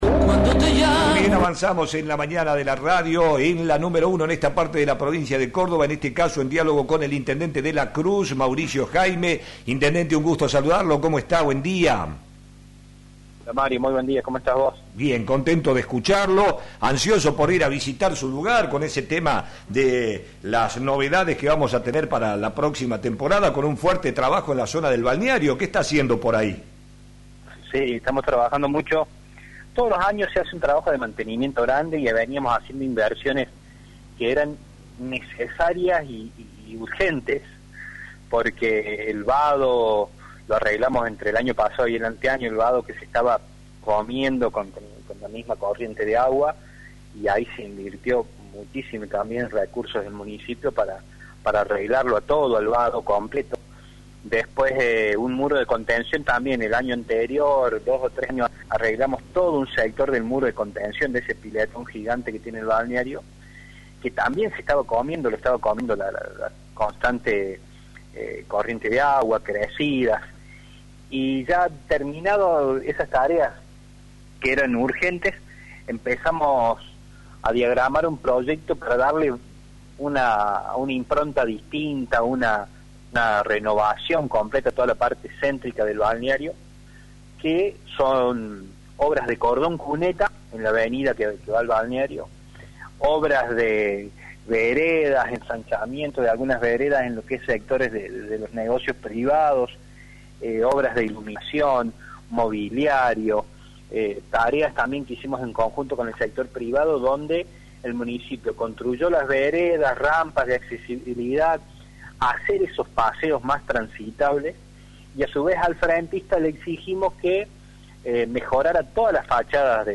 El intendente de La Cruz Mauricio Jaime habló en Flash FM sobre las obras que se realizan en el balneario municipal resaltando que se lograron con fondos propios y agradeció el acompañamiento del sector privado. Luego se refirió a las elecciones del domingo resaltando la figura de Rodrigo de Loredo.